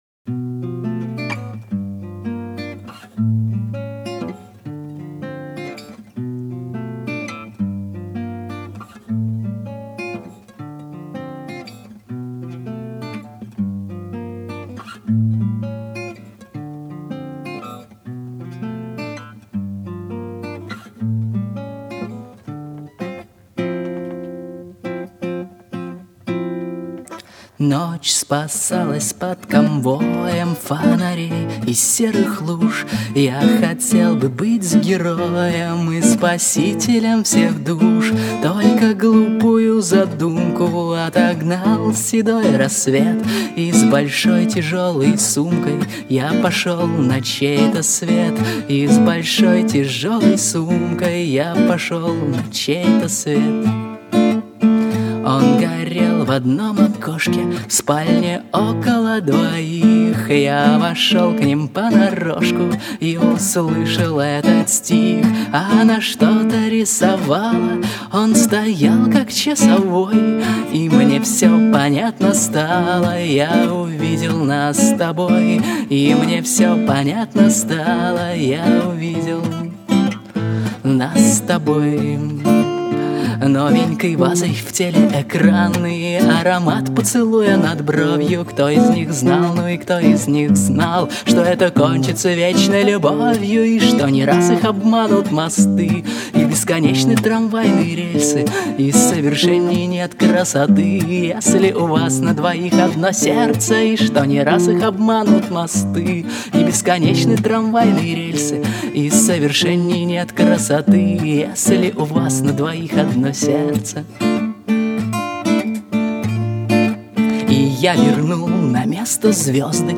У нас в гостях автор-исполнитель